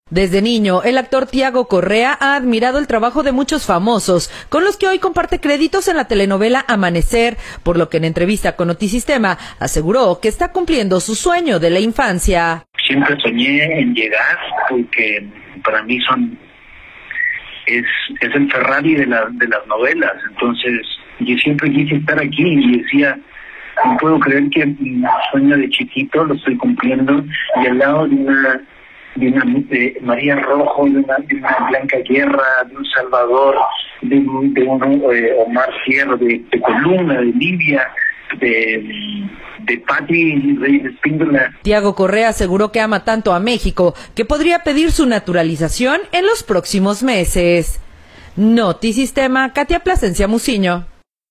Desde niño el actor Tiago Correa ha admirado el trabajo de muchos famosos con los que hoy comparte créditos en la telenovela “Amanecer”, por lo que en entrevista con Notisistema, aseguró que está cumpliendo su sueño de infancia.